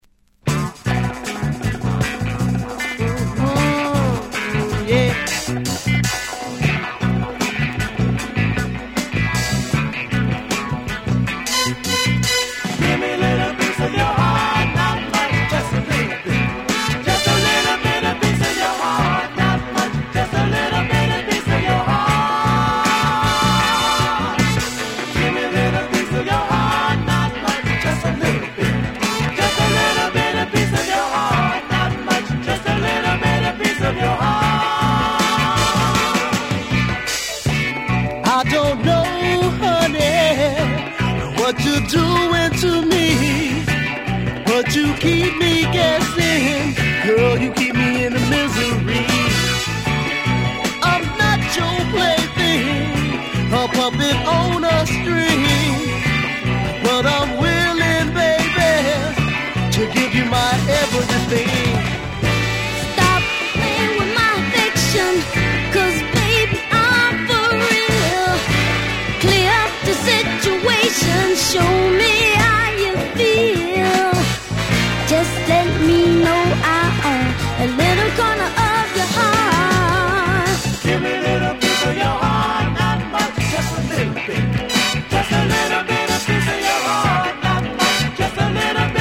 Genre: RARE SOUL